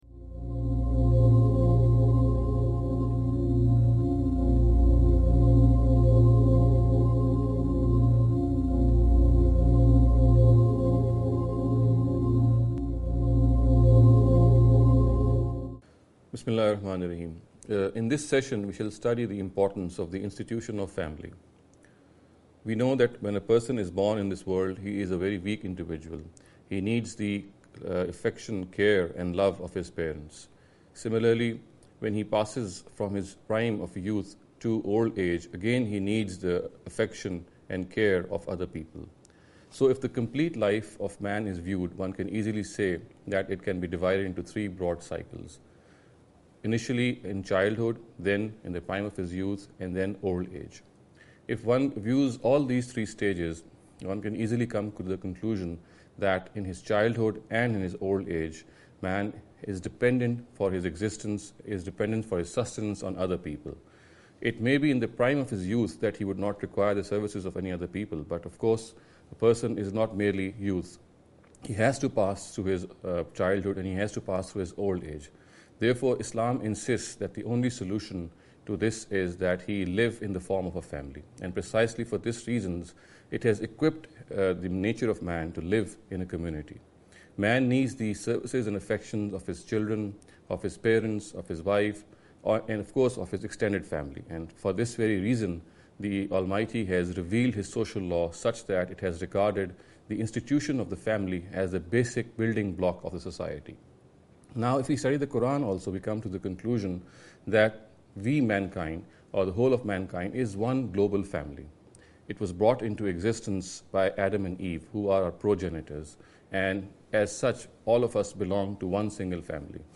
This lecture series will deal with some misconception regarding the Understanding The Qur’an.